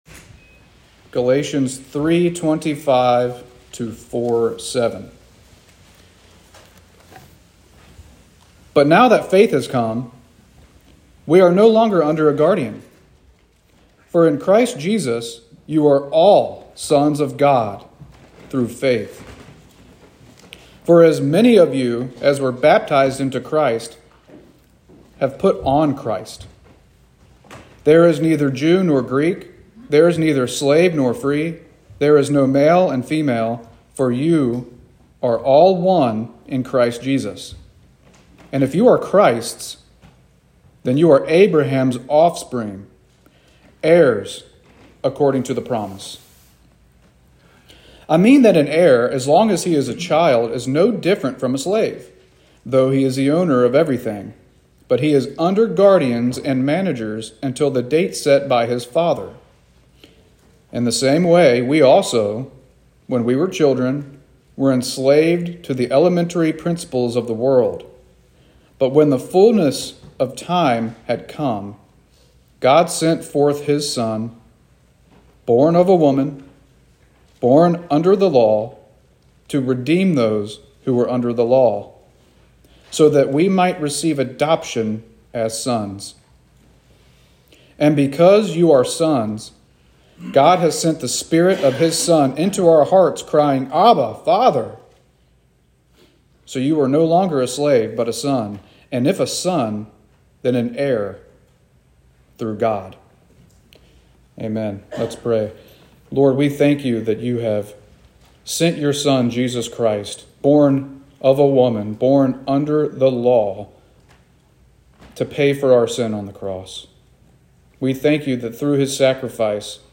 Sermons | Monterey Baptist Church